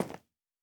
added stepping sounds
Tile_Mono_04.wav